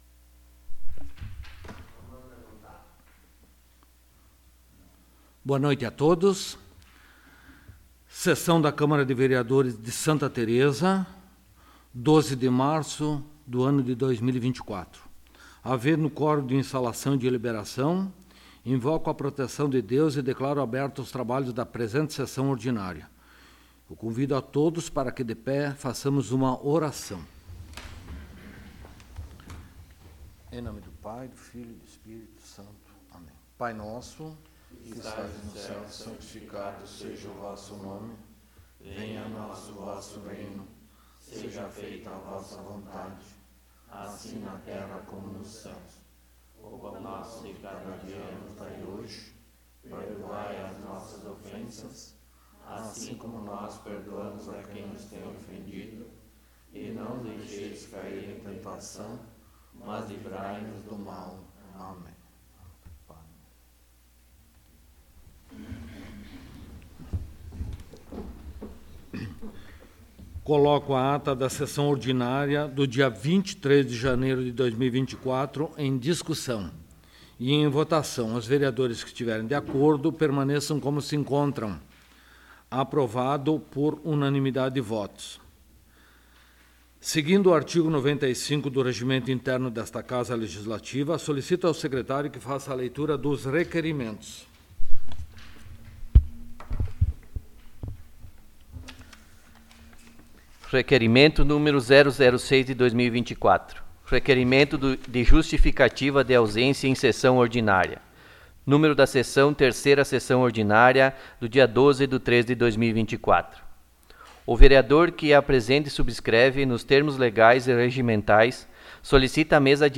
3ª Sessão Ordinária de 2024
Local: Câmara Municipal de Vereadores de Santa Tereza